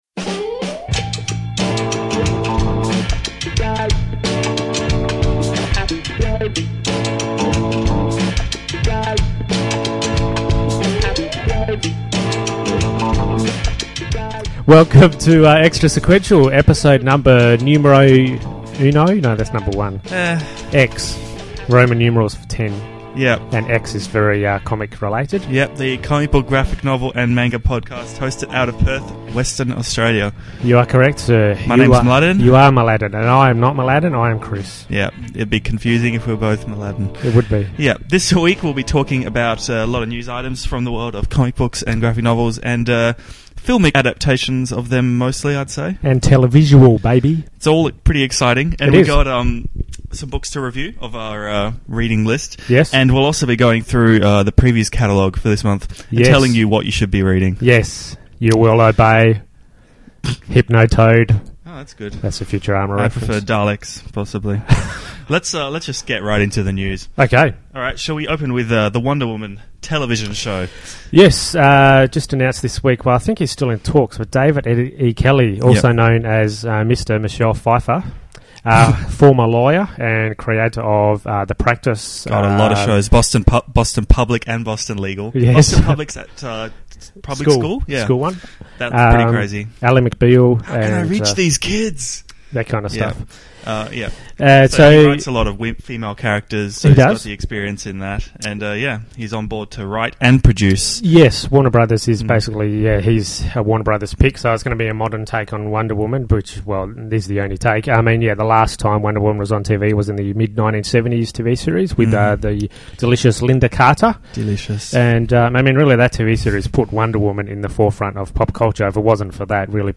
Not a Kevin Costner film. 39:58 PANEL PLAY We act out some dialogue from a recent comic.